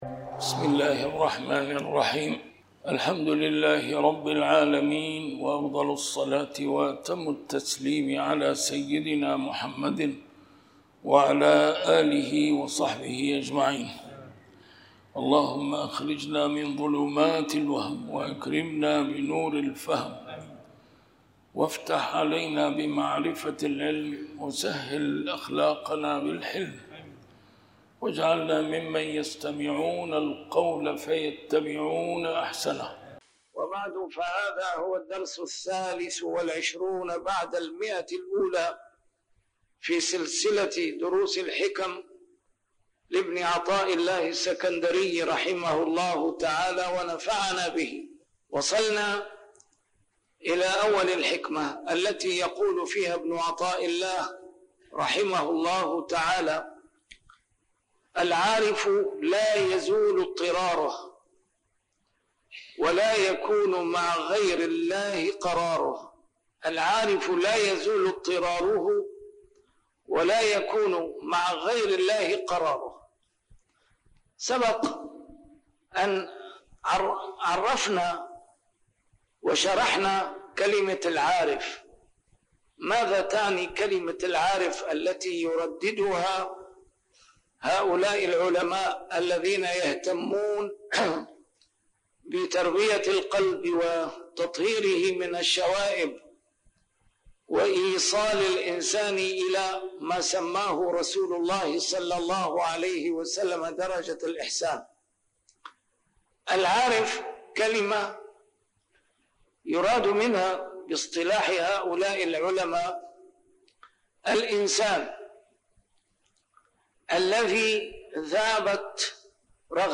A MARTYR SCHOLAR: IMAM MUHAMMAD SAEED RAMADAN AL-BOUTI - الدروس العلمية - شرح الحكم العطائية - الدرس رقم 123 شرح الحكمة 103